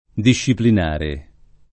disciplinare [ diššiplin # re ] agg.